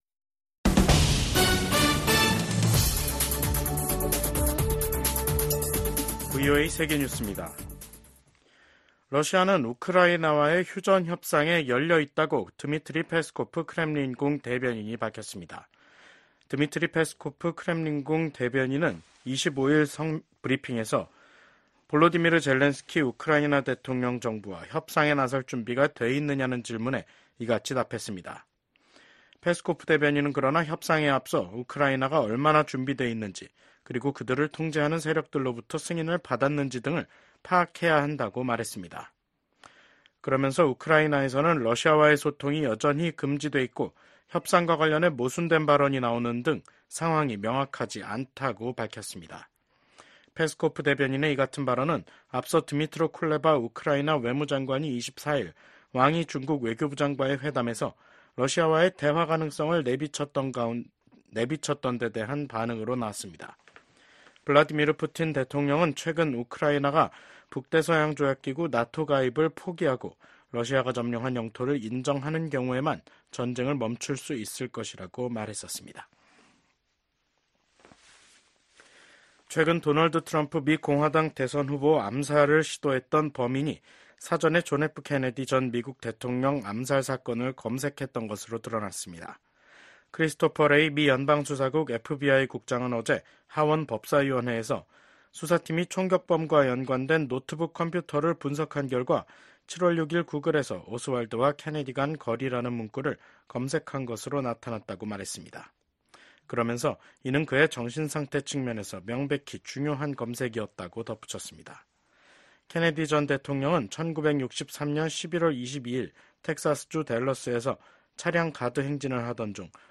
VOA 한국어 간판 뉴스 프로그램 '뉴스 투데이', 2024년 7월 25일 3부 방송입니다. 민주당 대선 후보직에서 사퇴한 조 바이든 미국 대통령이 대국민 연설을 통해 민주주의의 수호화 통합을 강조했습니다. 미국 정부가 북한의 미사일 관련 기술 개발을 지원한 중국 기업과 중국인에 신규 제재를 부과했습니다. 북한과 러시아의 관계가 급속도로 가까워지는 가운데 한국과 중국은 외교차관 전략대화를, 북한은 벨라루스와 외교장관 회담을 가졌습니다.